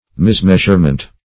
Mismeasurement \Mis*meas"ure*ment\, n.
mismeasurement.mp3